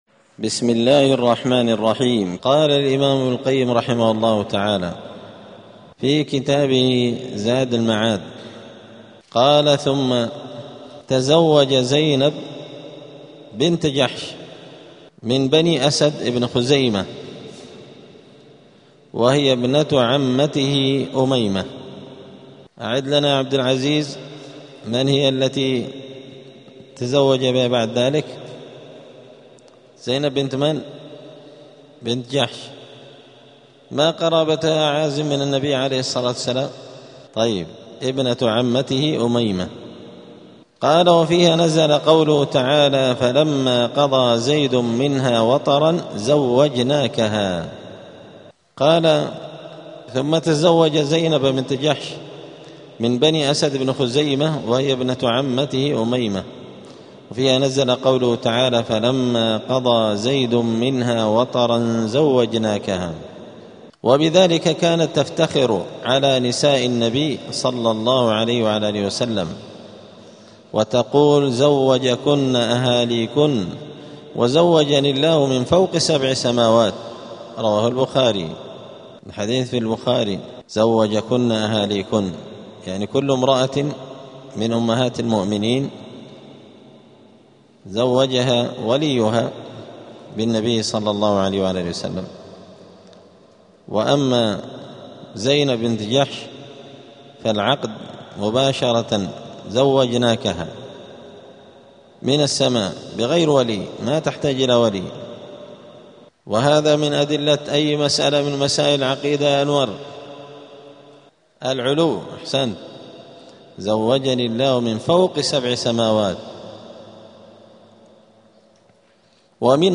*الدرس التاسع عشر (19) {زواج النبي صلى الله عليه وسلم بزينب بنت جحش}.*